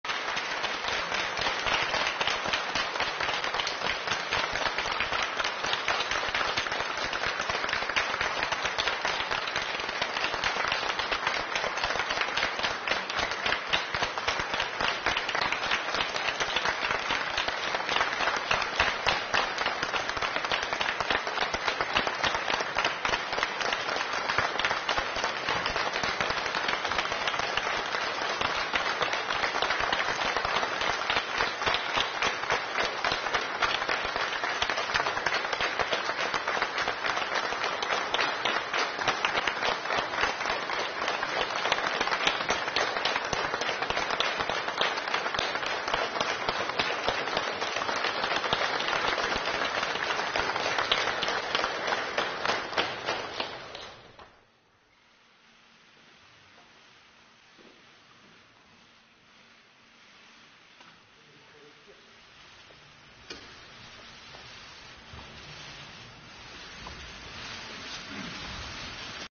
习近平总书记在二十届中共中央政治局常委同中外记者见面时的讲话（现场实录）
据新华社报道，10月23日，刚刚在中国共产党第二十届中央委员会第一次全体会议上当选的中共中央总书记习近平和中共中央政治局常委李强、赵乐际、王沪宁、蔡奇、丁薛祥、李希在北京人民大会堂同中外记者亲切见面。